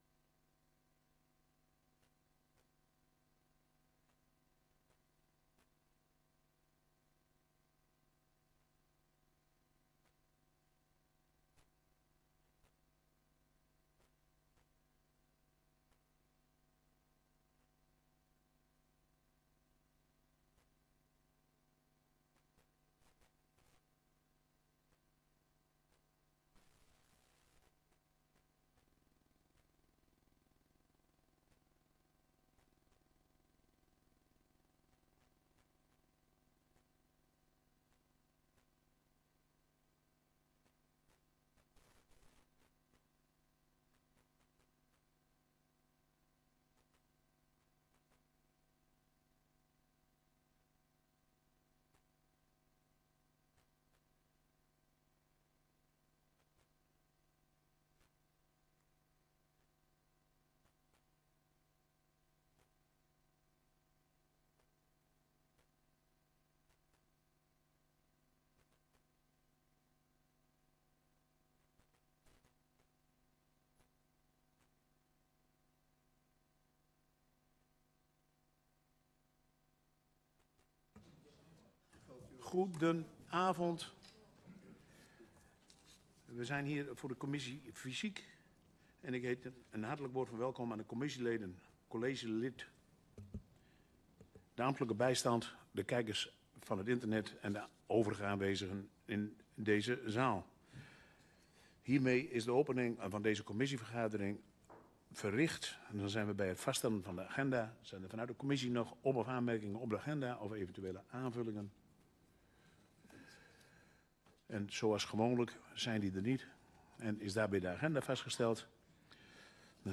Download de volledige audio van deze vergadering
Locatie: Raadszaal